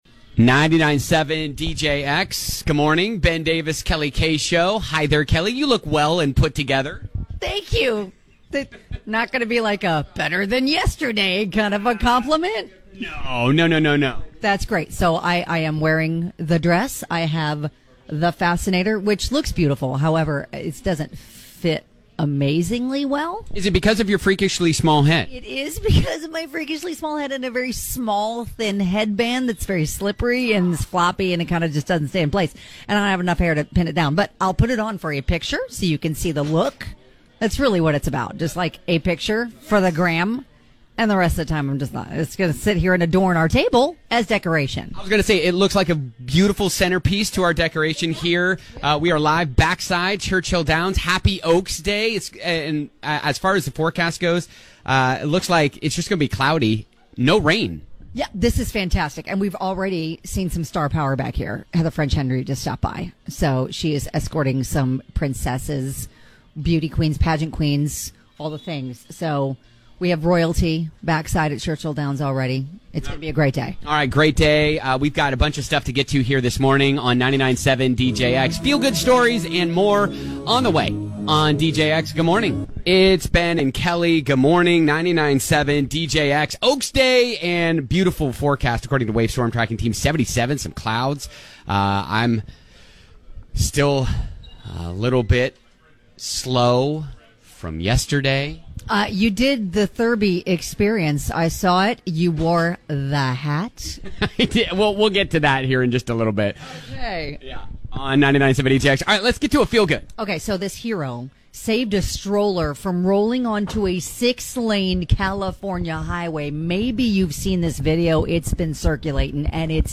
05/05/2023 The One Backside Churchill Downs On Oaks